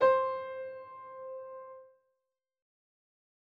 009_Piano Note.L.wav